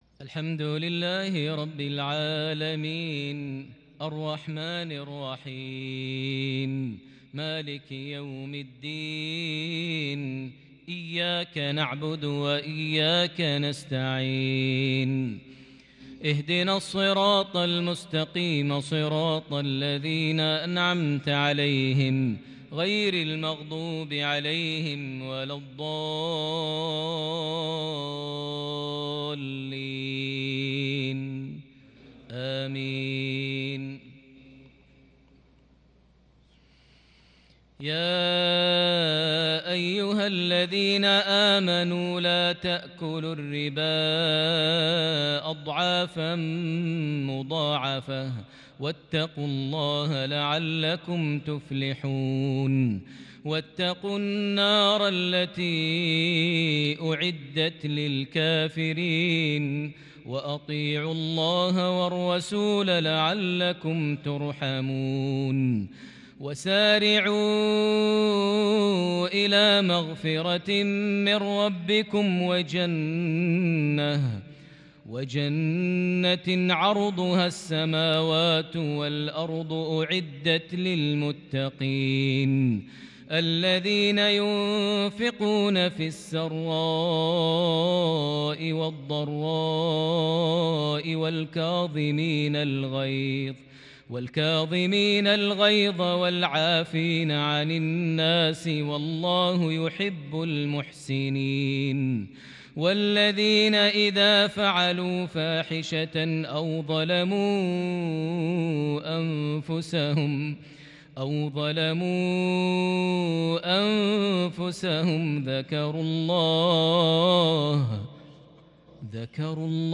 عشائية متألقة فريدة بالكرد من سورة آل عمران (130-145) | 20 رجب 1442هـ > 1442 هـ > الفروض - تلاوات ماهر المعيقلي